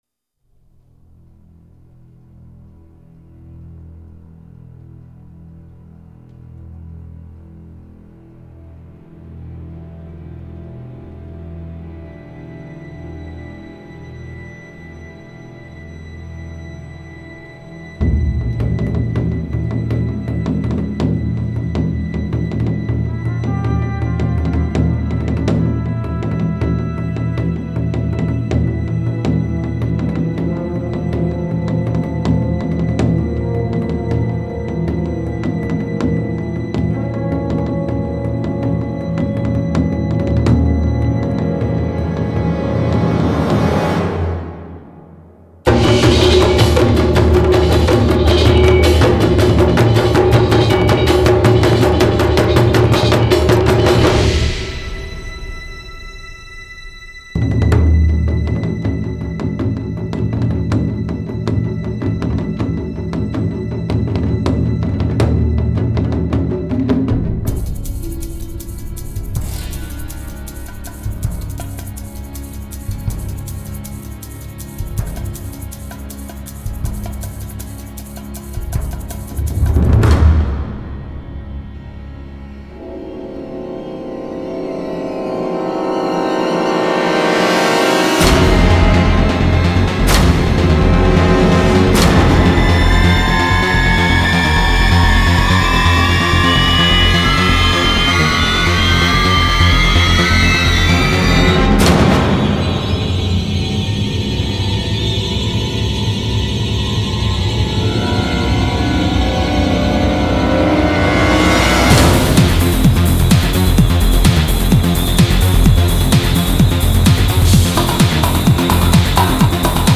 你追求极至的低频么？我们从收藏的唱片中精选了这么十首，这十首曲子可以让95%的音箱颜面扫地。